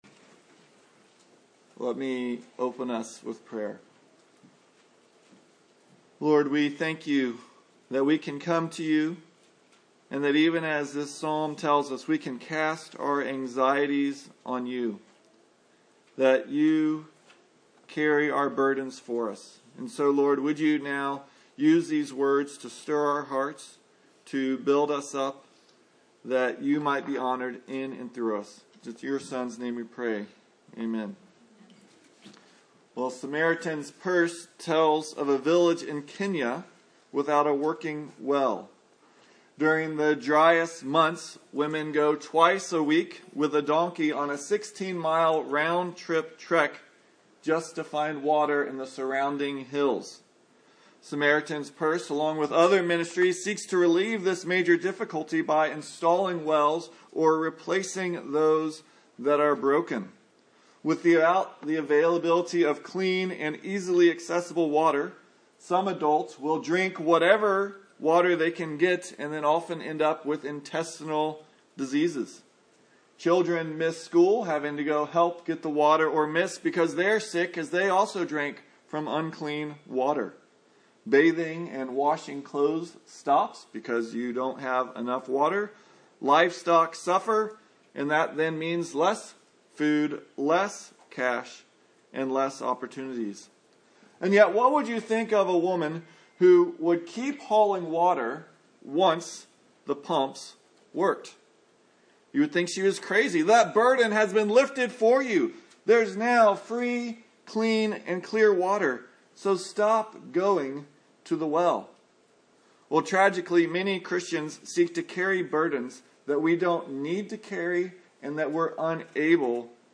2025 The Sustaining God Preacher